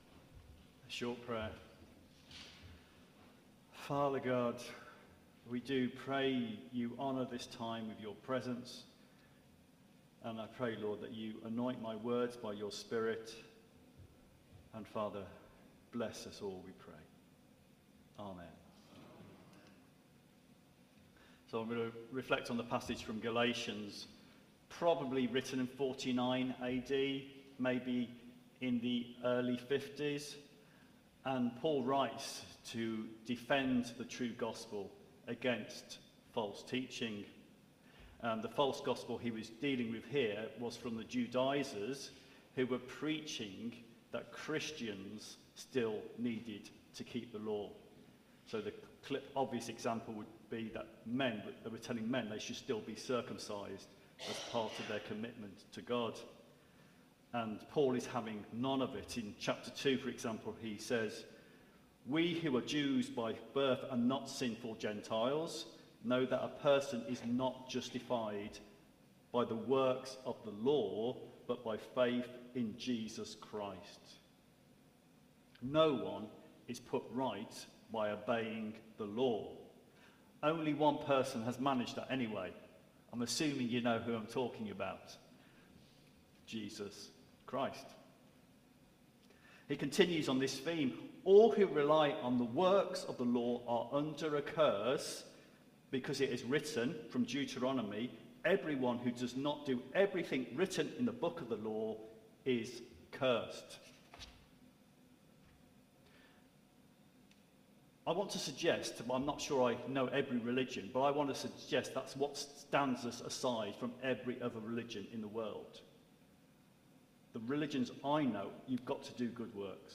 Theme: Saved by faith alone Sermon Search